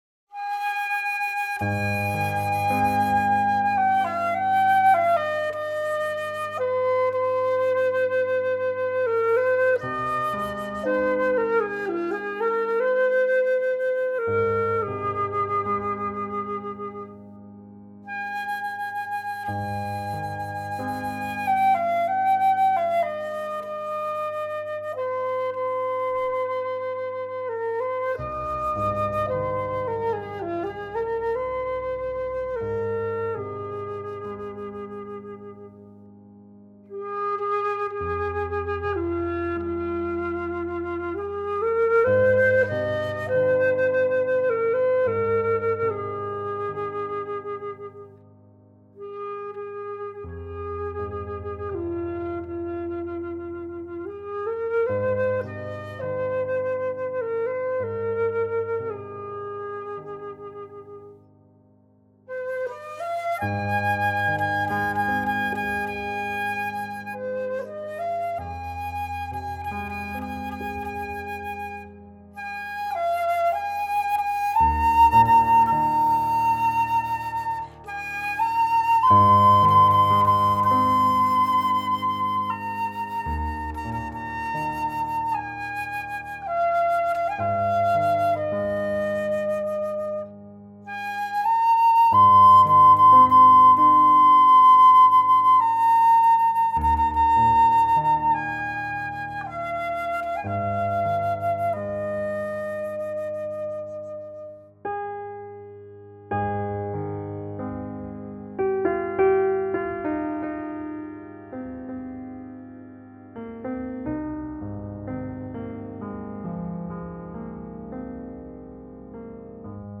per flauto e pianoforte.